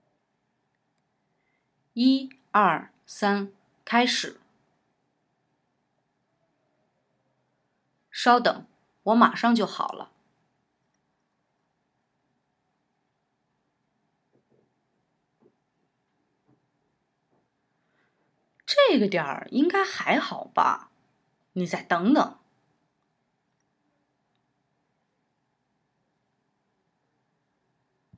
Höre dir Audio 3 an, in dem nur Part B eingesprochen wurde, und übernimm diesmal Part A! Damit du weißt, wann du einsetzen musst, gibt es vorab ein Startsignal.
Übung 3: Sprich Part A!